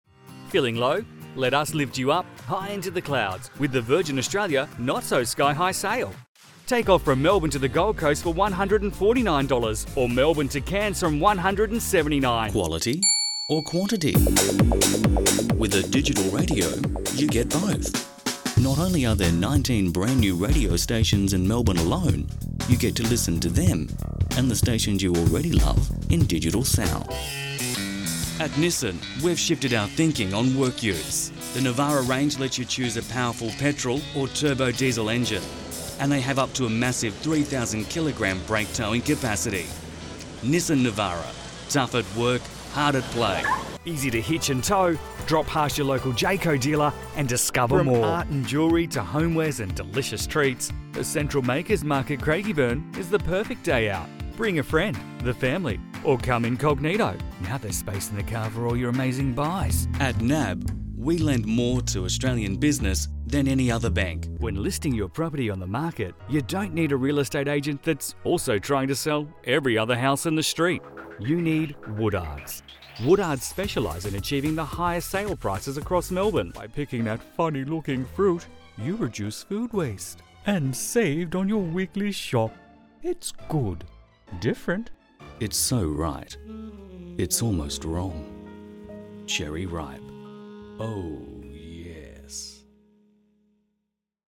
Male
My voice is well rounded & I can easily adapt my tone, pitch and pace to get you the voice you're looking for. Deep & engaging - light & entreating.
Radio Commercials
Words that describe my voice are Trusted, Clear, Confident.